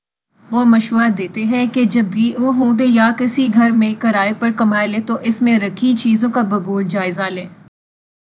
deepfake_detection_dataset_urdu / Spoofed_TTS /Speaker_04 /117.wav